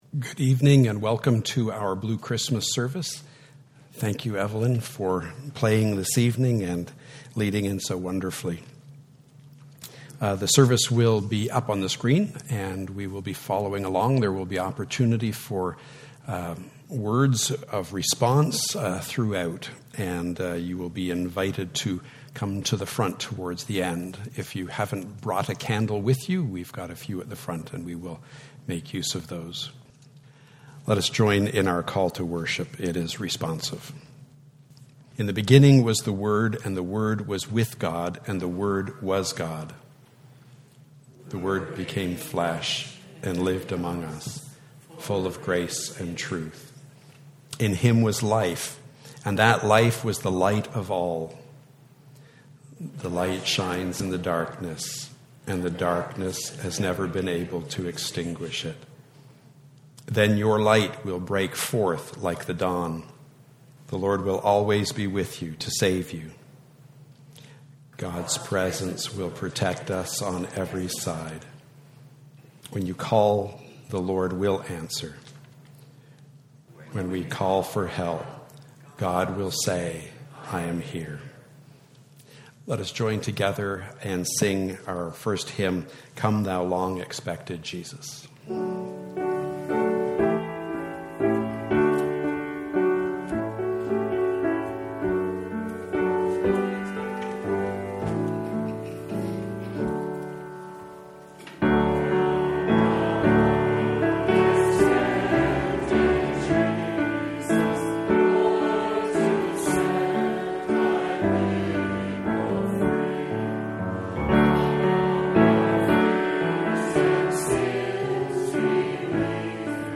Message: "Blue Christmas Service"